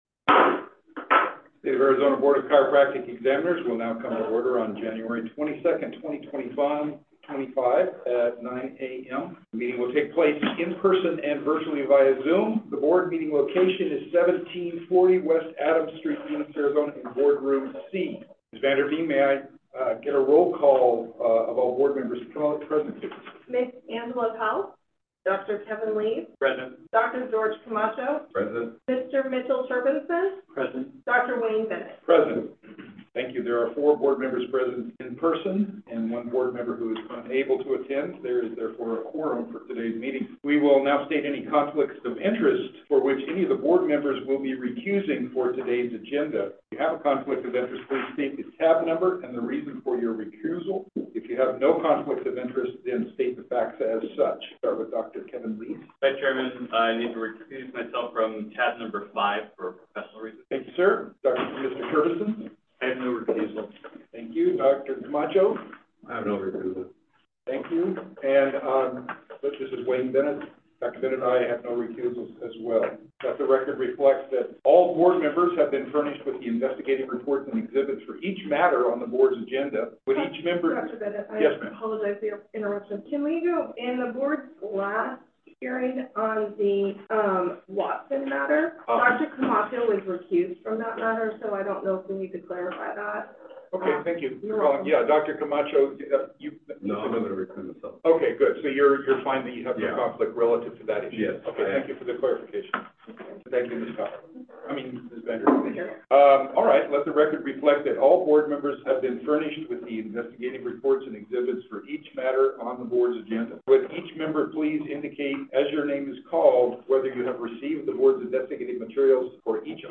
December 11, 2024, Regular Board Meeting | Board of Chiropractic Examiners
NOTICE & AGENDA FOR REGULAR SESSION MEETING Wednesday, December 11, 2024 at 9:00 a.m. 1740 West. Adams Street, Phoenix, Arizona, 85007 Boardroom C